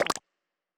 Click (11).wav